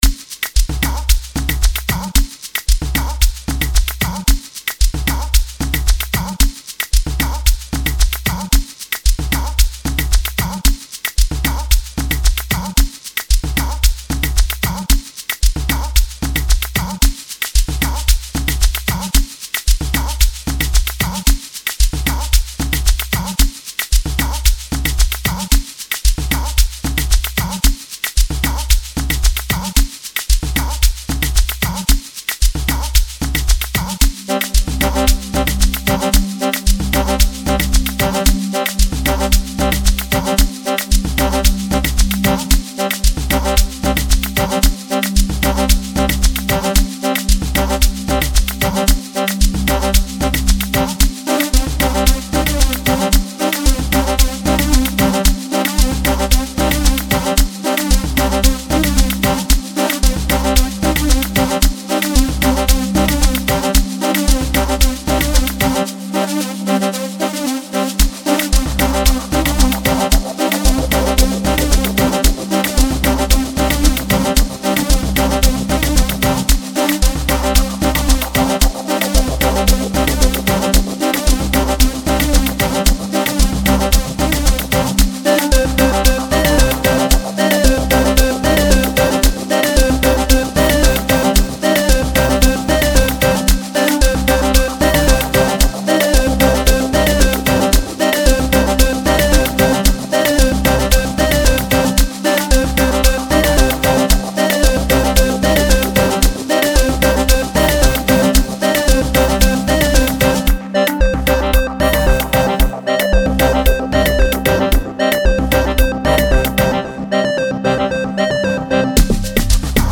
Ever wondered what Amapiano Tech is?